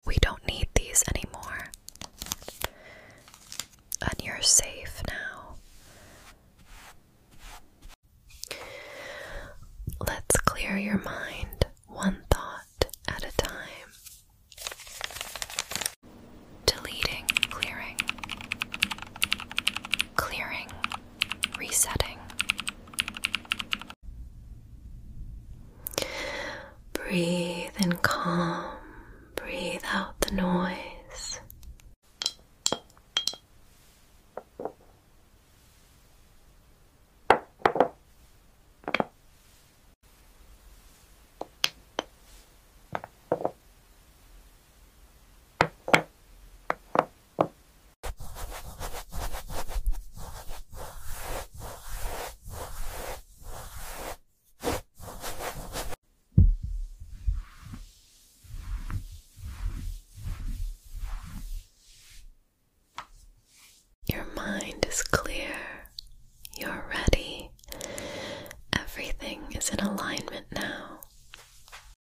Upload By PREMIUM AI ASMR